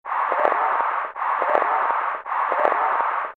hooting owl, or, if a little higher, a
gd6-cuckoo.mp3